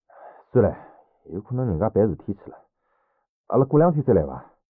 三楼/囚室/肉铺配音偷听效果处理；